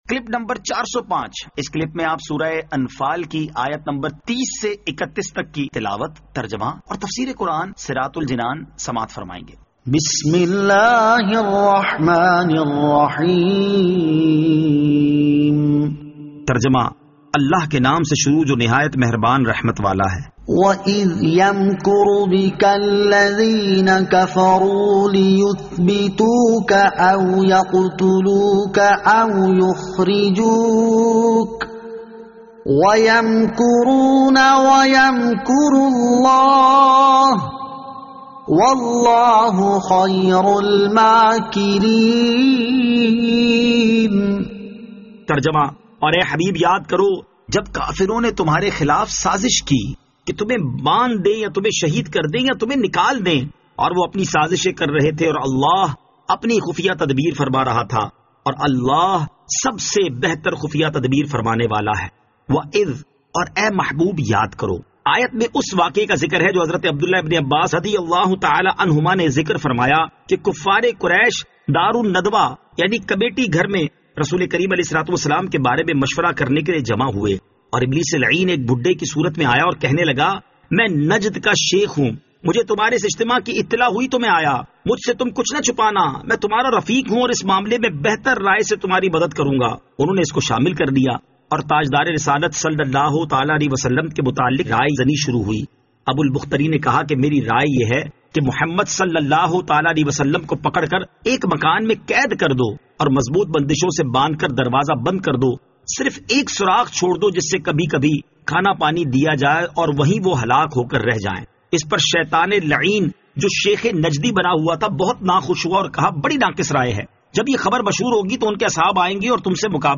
Surah Al-Anfal Ayat 30 To 31 Tilawat , Tarjama , Tafseer